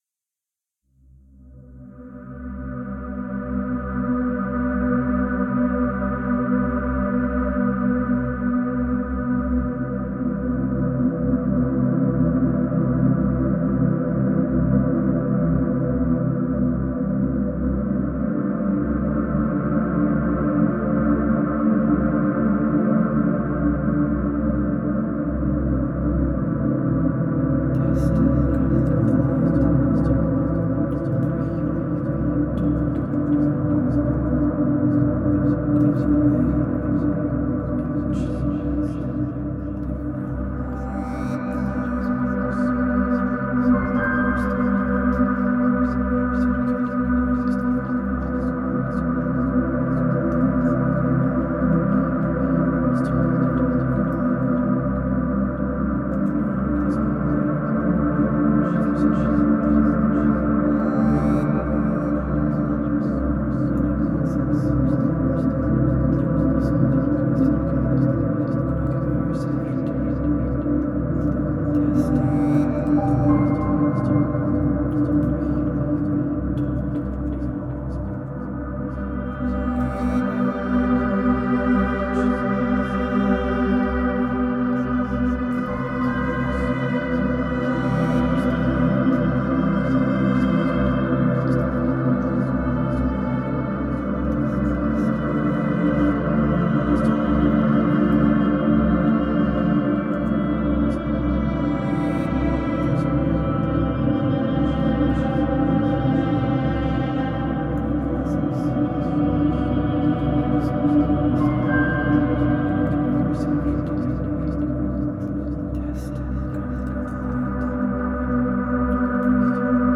I was going to place this on my ambient album, but I will post it for you guys :P